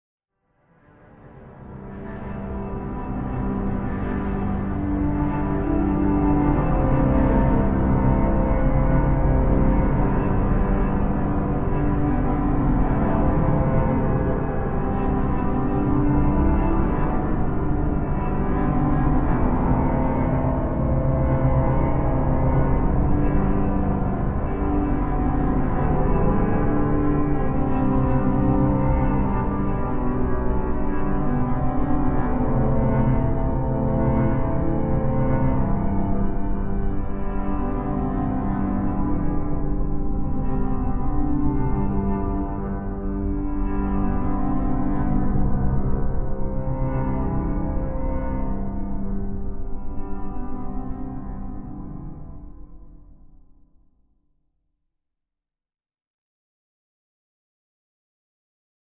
Music: Eerie Stretched Piano, With Reverb And Low Rumble.